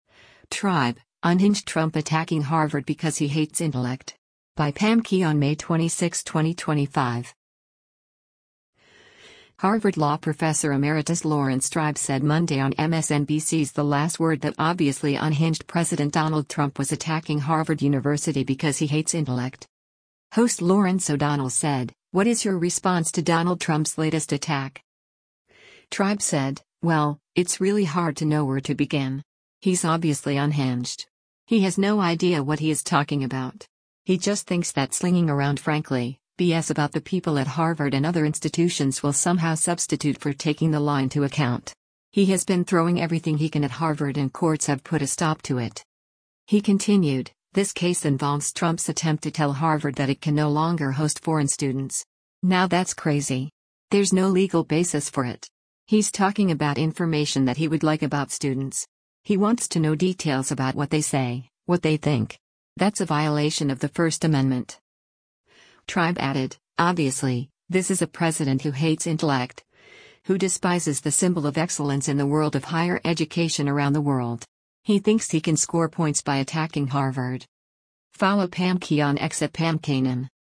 Harvard Law professor emeritus Laurence Tribe said Monday on MSNBC’s “The Last Word” that “obviously unhinged” President Donald Trump was attacking Harvard University because he “hates intellect.”
Host Lawrence O’Donnell said, “What is your response to Donald Trump’s latest attack?”